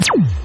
tlaser-turret-fire.ogg